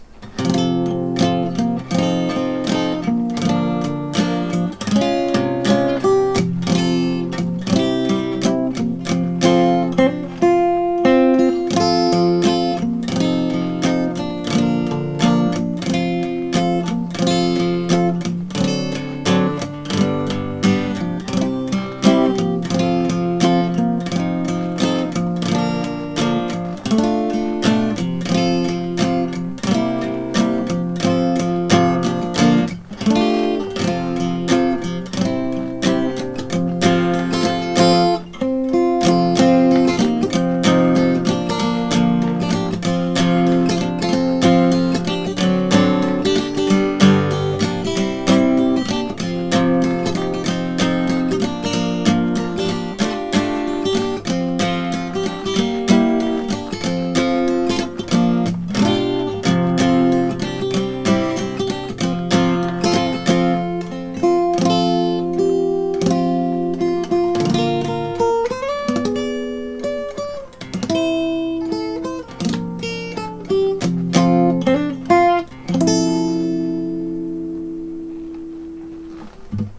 コード進行はこちら。カポ3で弾くと原曲キーになります↓
イントロからアウトロまでこんな感じで弾いてみました↓↓↓
アウトロはまさかのソロギターです。
2番を激しく弾いて、アウトロをまろやかに終わらせる、そんなイメージで。